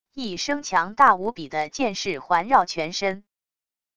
一声强大无比的剑势环绕全身wav音频